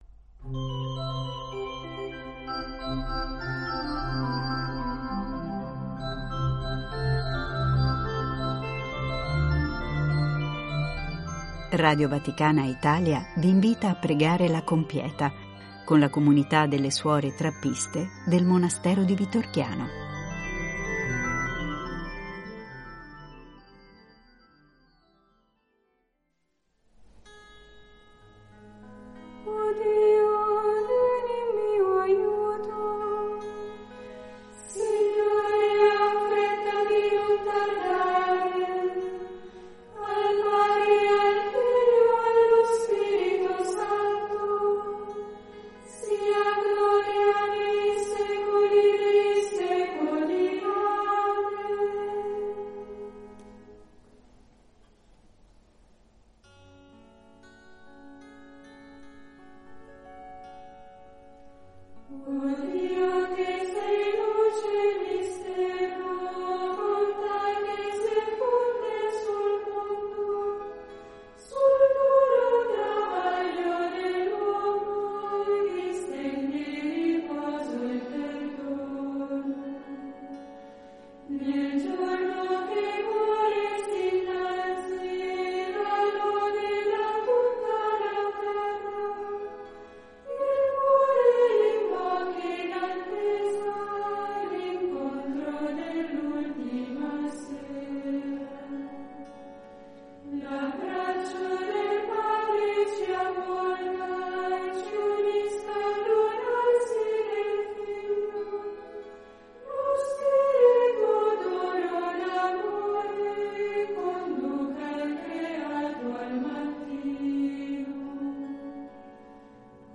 Dal Monastero delle suore Trappiste di Vitorchiano.